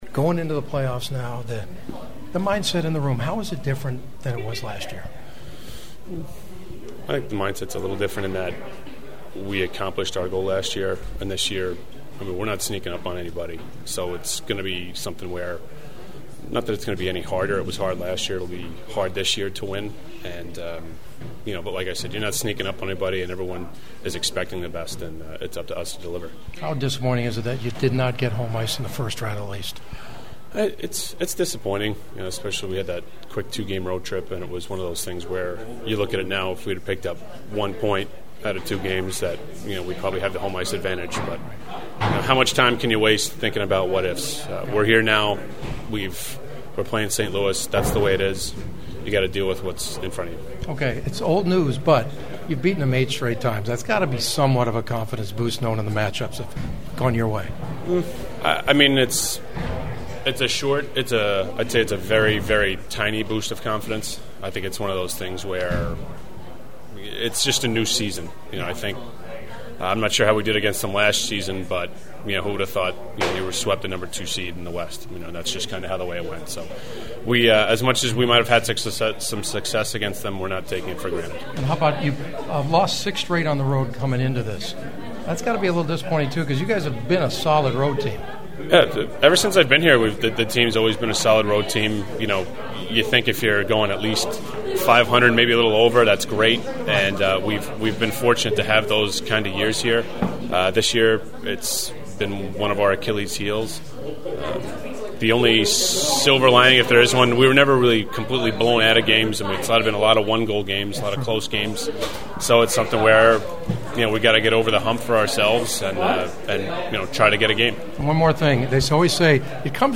Defenseman Rob Scuderi who owns 2 Stanley Cup rings (one in Pittsburgh) always is straight forward and actually speaks his mind like an old-schooler gave me some great takes on the rough but hopeful road ahead…